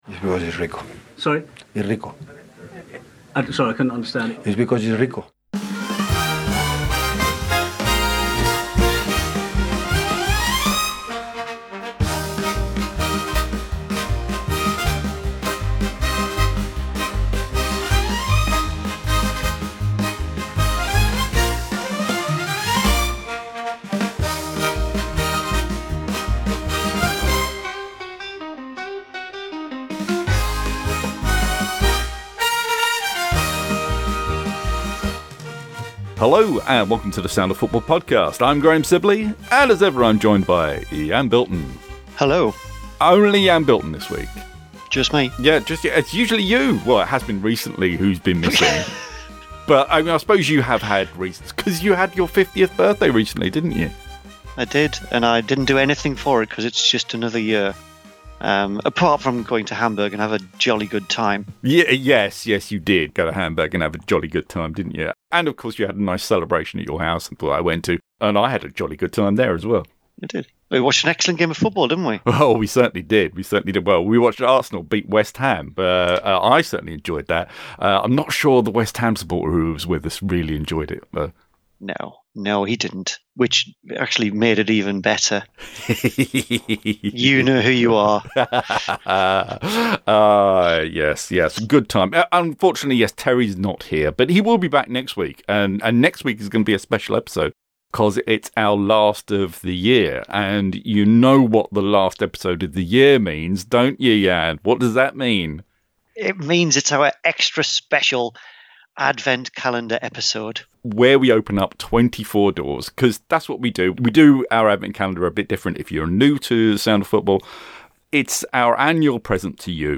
Also this week, as an extra yuletide treat for our listeners, we've included a newly remastered version of our 2007 production of Dicken's Christmas Carol with a football twist.